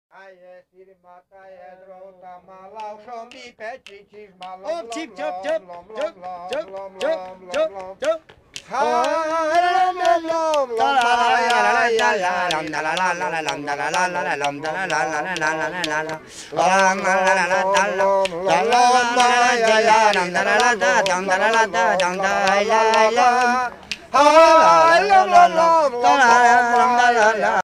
Tancdallam énekelve, majd hegedun
Pièce musicale éditée